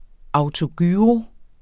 Udtale [ ɑwtoˈgyːʁo ]